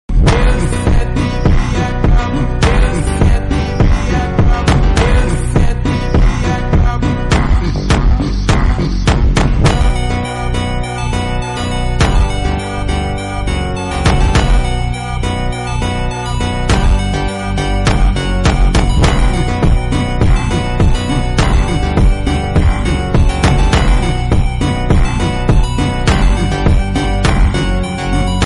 hehe sound effects free download